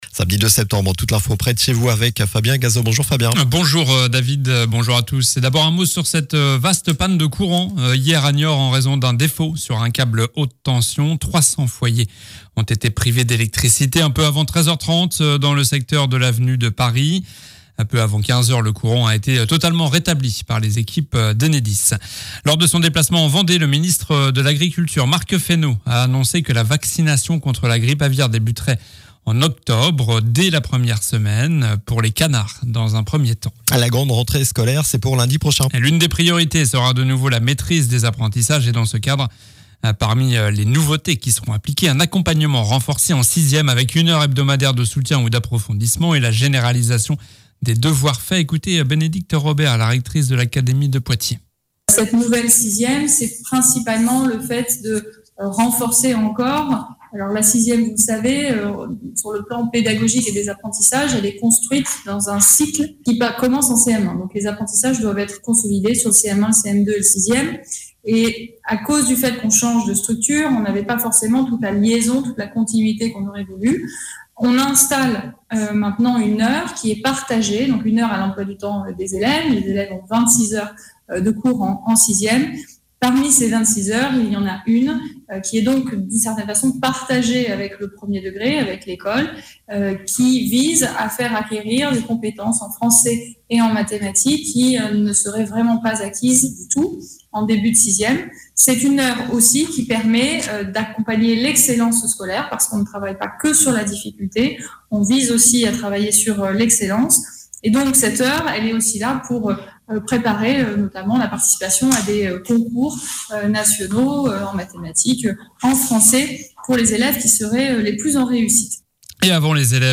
Journal du samedi 02 septembre